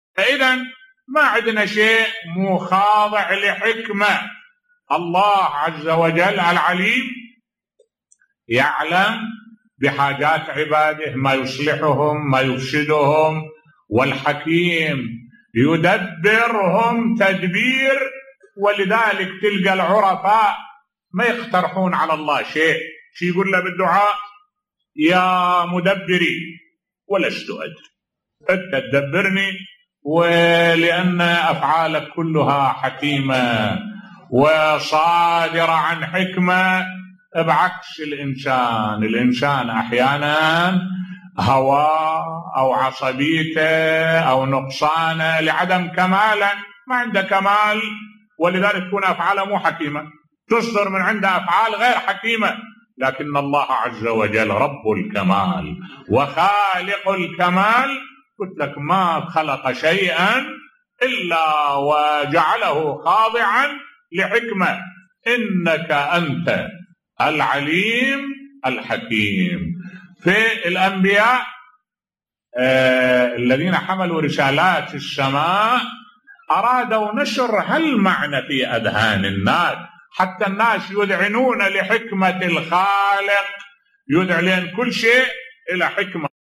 ملف صوتی كيف يدعوا أولياء الله بصوت الشيخ الدكتور أحمد الوائلي